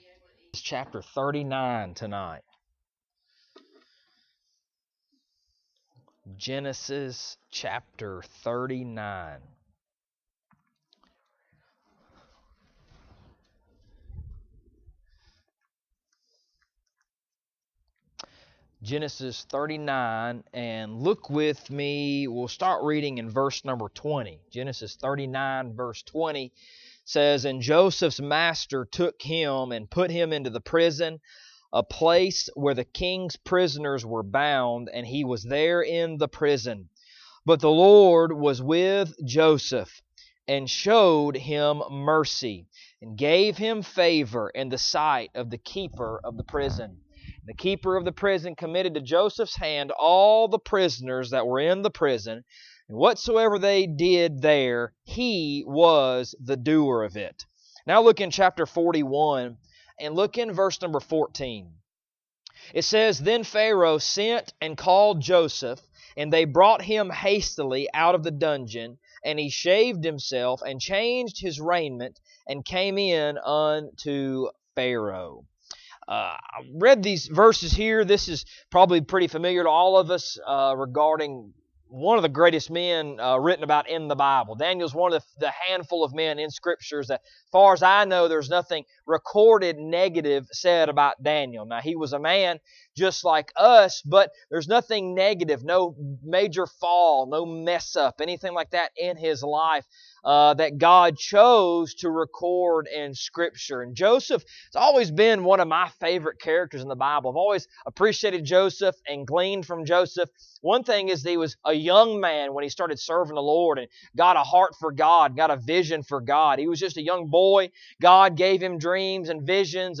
Genesis 39:20 41:14 Service Type: Wednesday Evening « Bless The Lord